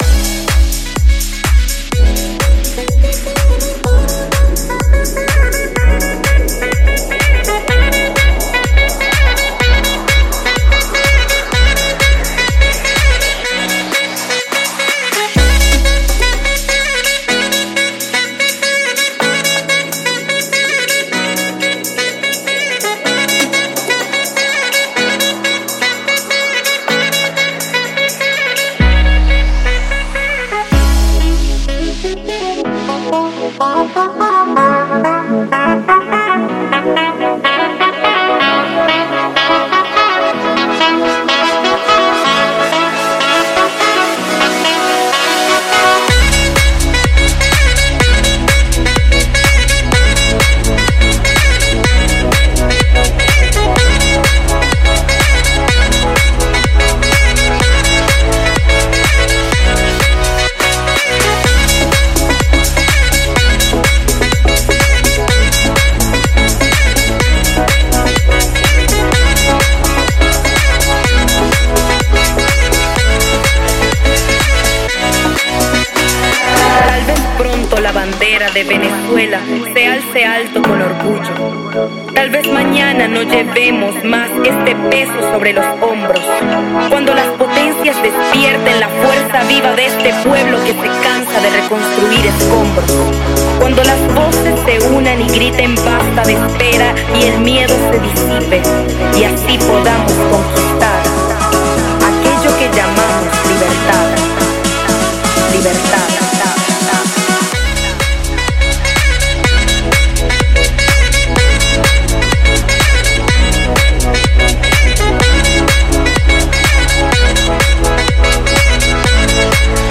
پلی لیست بهترین آهنگ های ساکسوفون (بی کلام)
Saxophone